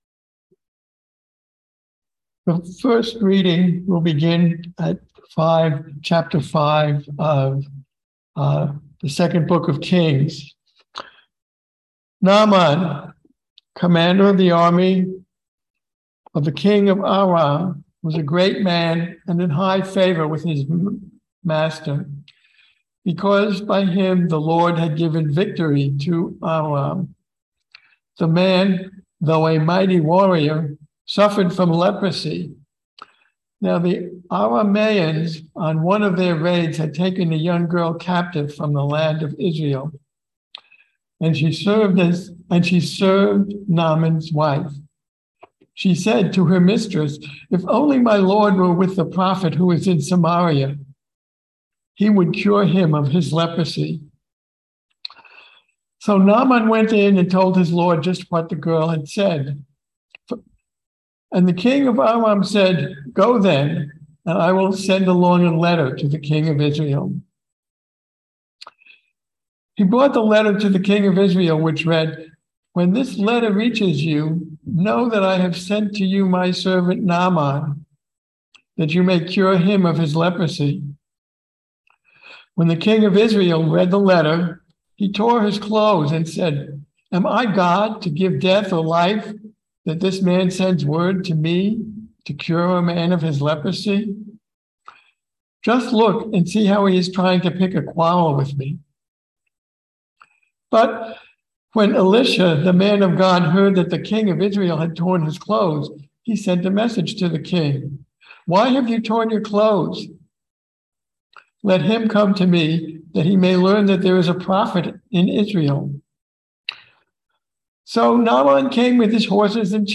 Listen to the most recent message from Sunday worship at Berkeley Friends Church, “The God of Israel.”